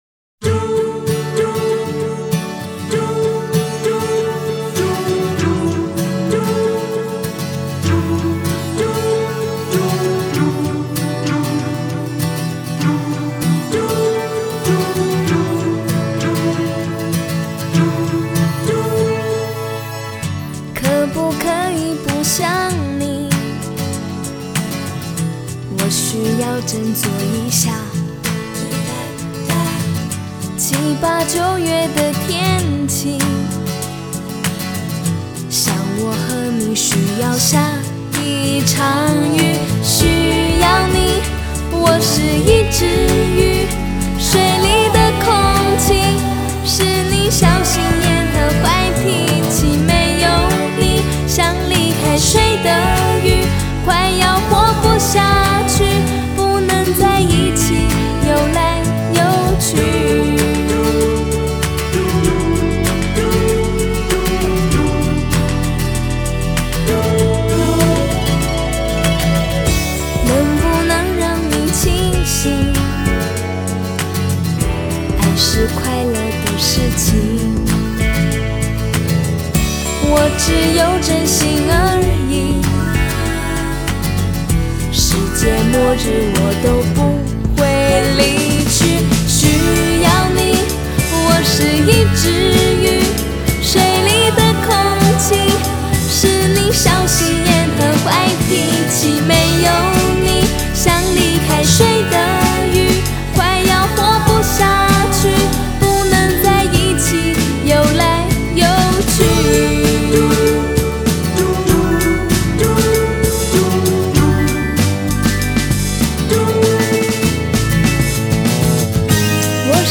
Ps：在线试听为压缩音质节选，体验无损音质请下载完整版 嘟…